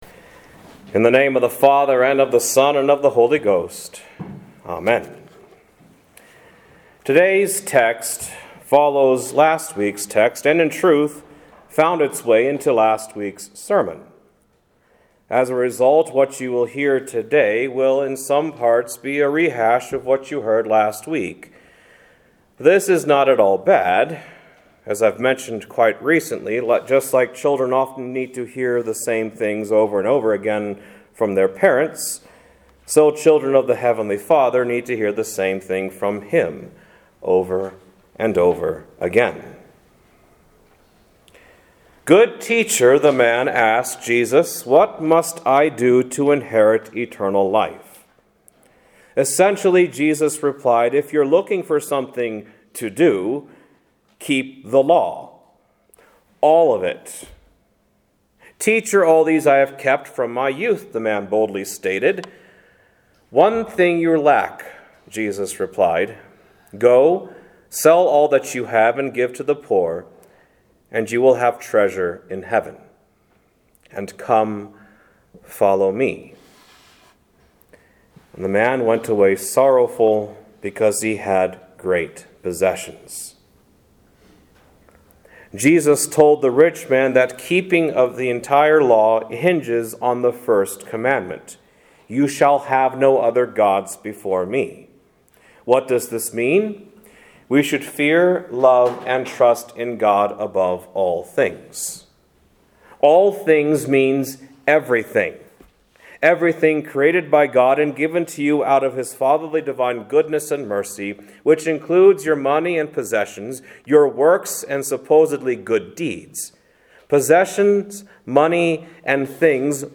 Today’s text follows last week’s text, and in truth, found it’s way into last week’s sermon.